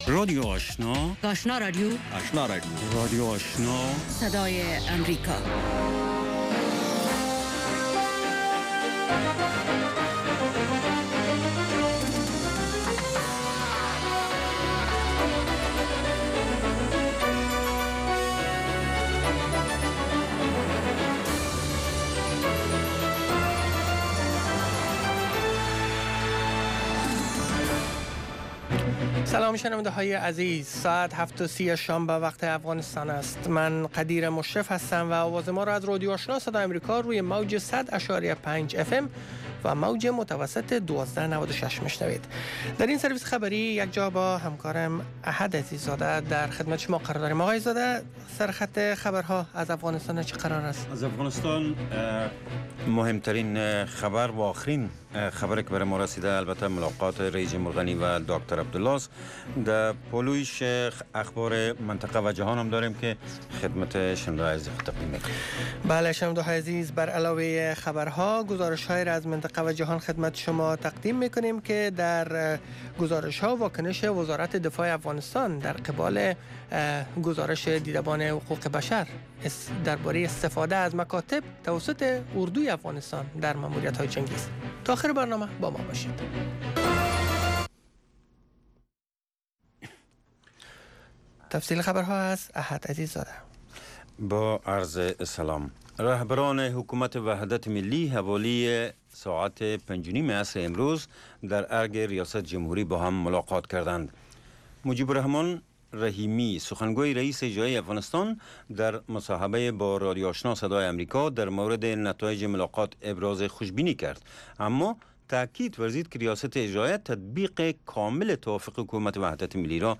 نخستین برنامه خبری شب
در نخستین برنامه خبری شب خبرهای تازه و گزارش های دقیق از سرتاسر افغانستان، منطقه و جهان فقط در سی دقیقه.